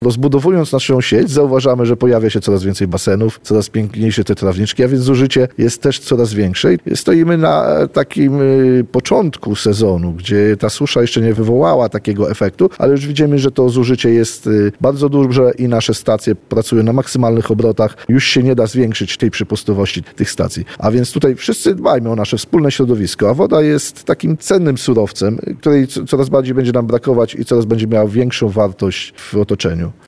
A tymczasem gmina inwestuje ogromne pieniądze w rozbudowę infrastruktury wodociągowej, tylko w tym roku i przyszłym 8 mln zł – mówi wójt Ludwina Andrzej Chabros.